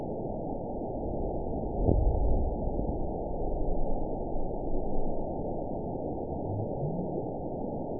event 920463 date 03/26/24 time 23:42:01 GMT (1 year, 1 month ago) score 9.34 location TSS-AB09 detected by nrw target species NRW annotations +NRW Spectrogram: Frequency (kHz) vs. Time (s) audio not available .wav